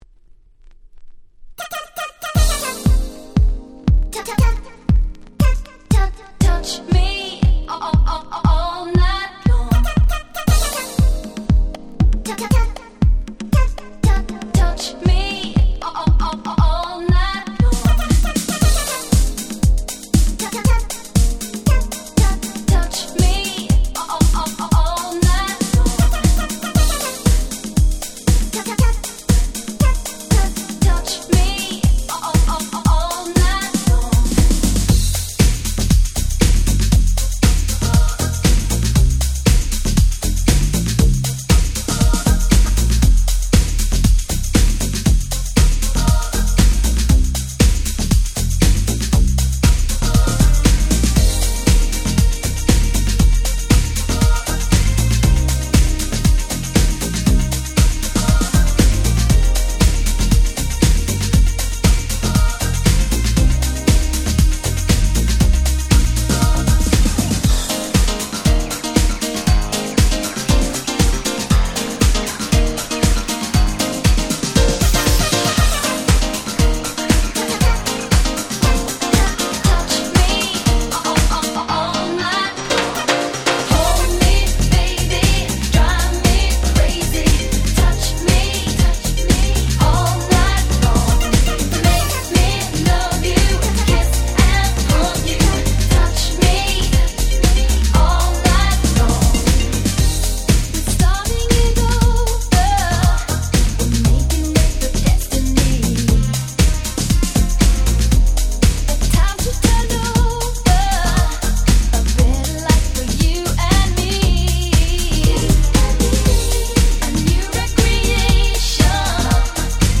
91' Super Hit R&B !!
Vocal House Classicsとしてもお馴染み！！